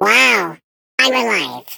Sfx_tool_spypenguin_vo_rebuilt_01.ogg